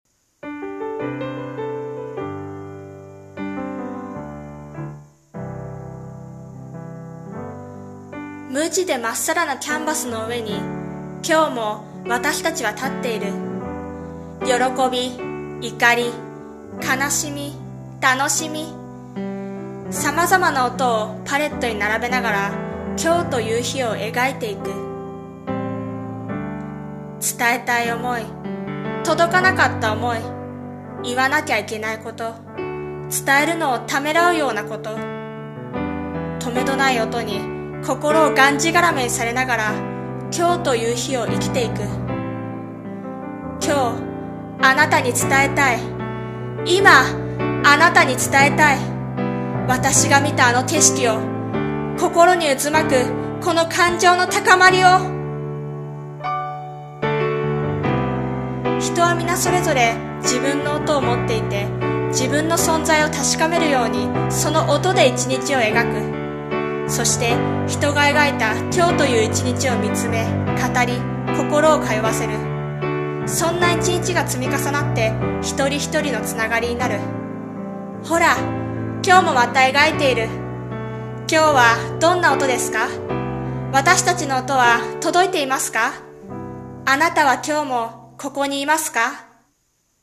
さんの投稿した曲一覧 を表示 朗読台本「存在色〜ソンザイシキ〜」